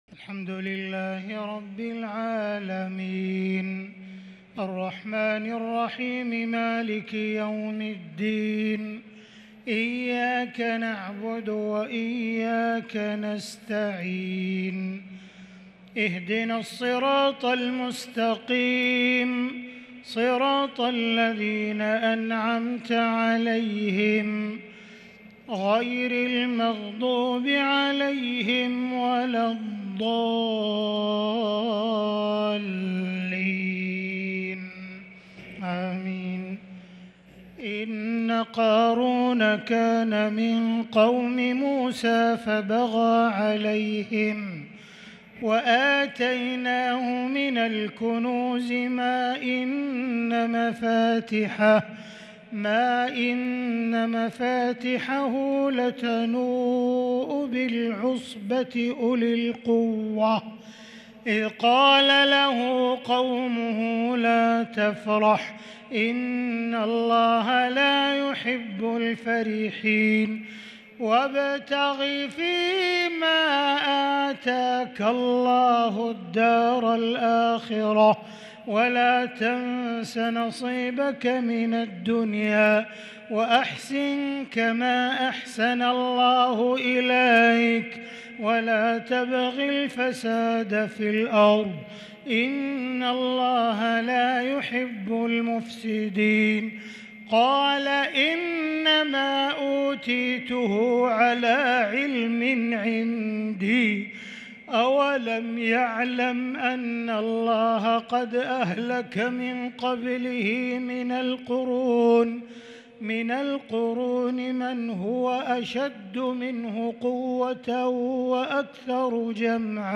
تهجد ليلة 23 رمضان 1443هـ من سورتي القصص (76-88) و العنكبوت (1-13) Tahajjud 23st night Ramadan 1443H Surah Al-Qasas and Al-Ankaboot > تراويح الحرم المكي عام 1443 🕋 > التراويح - تلاوات الحرمين